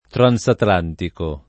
vai all'elenco alfabetico delle voci ingrandisci il carattere 100% rimpicciolisci il carattere stampa invia tramite posta elettronica codividi su Facebook transatlantico [ tran S atl # ntiko ] agg. e s. m.; pl. m. ‑ci